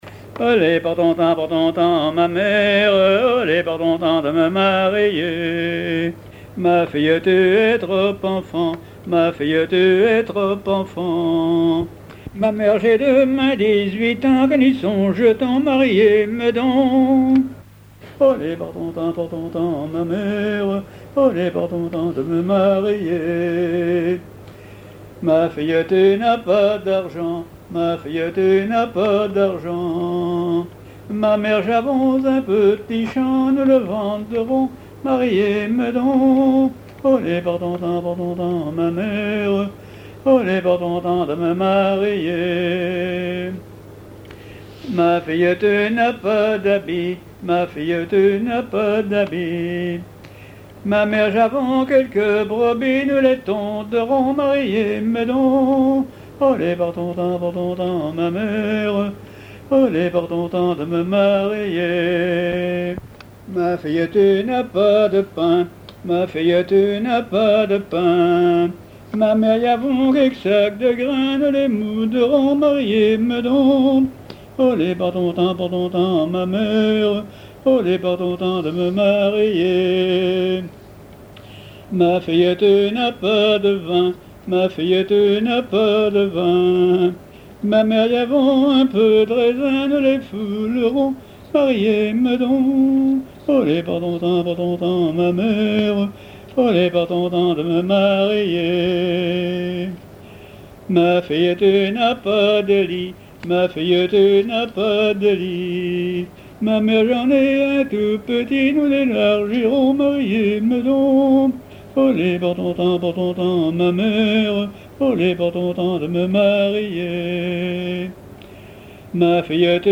Mémoires et Patrimoines vivants - RaddO est une base de données d'archives iconographiques et sonores.
Genre dialogue
chansons à ripouner ou à répondre
Catégorie Pièce musicale inédite